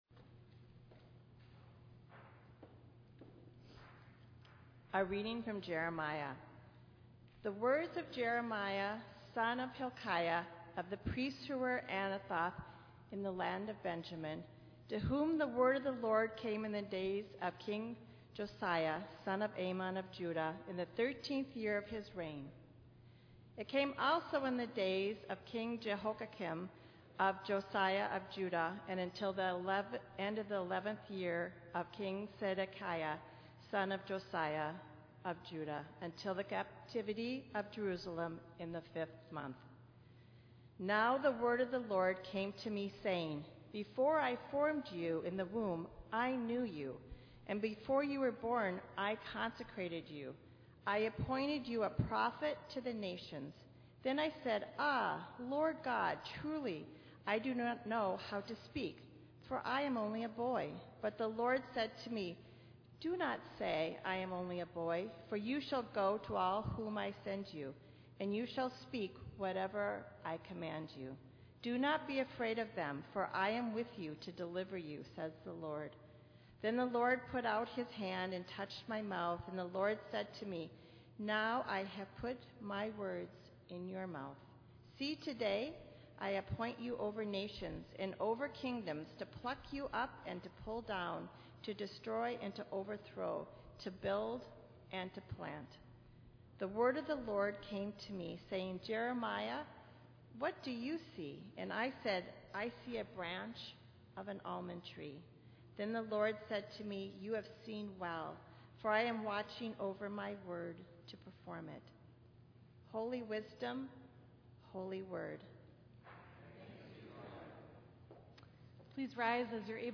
Minnetonka Livestream · Sunday, June 12, 2022 9:30 am